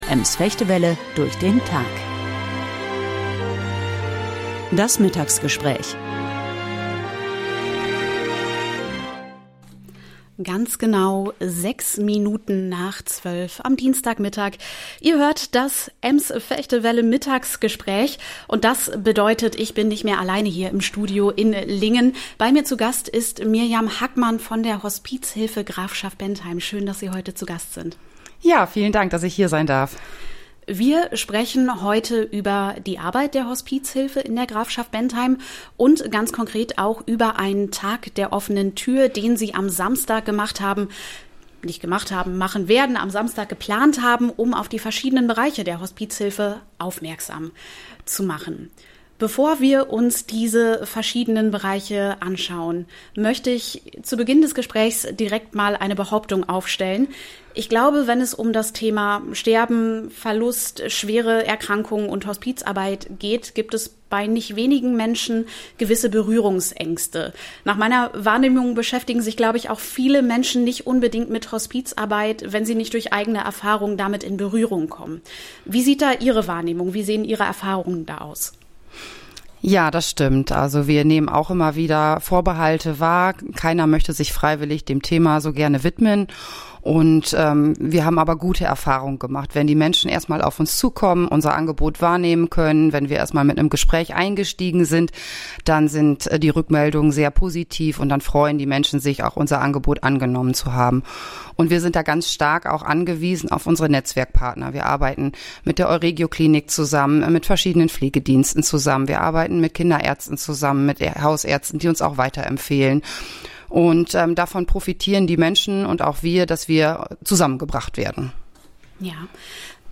Talk: Hospizhilfe Grafschaft Bentheim lädt zum Tag der offenen Tür ein - Ems Vechte Welle